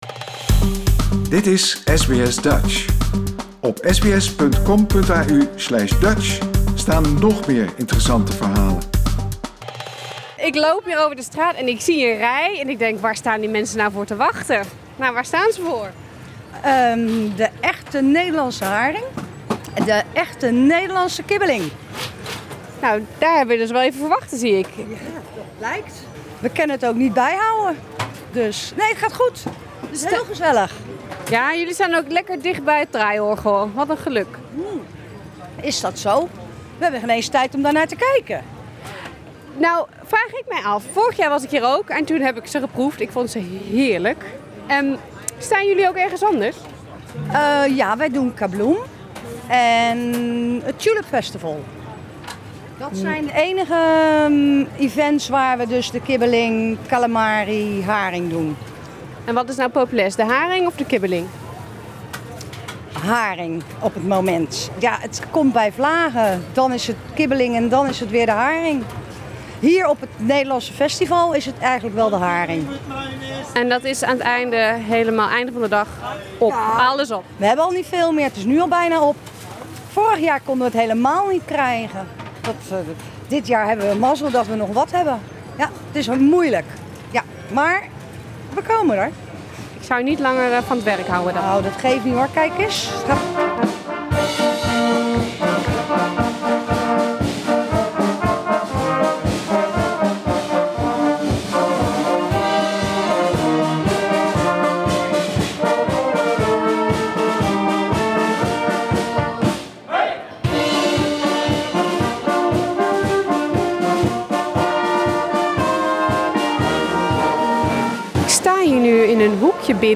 Een sfeerverslag.